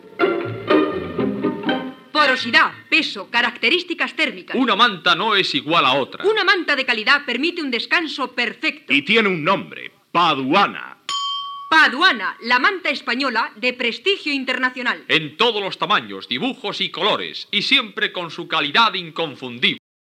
Anunci Mantas Paduana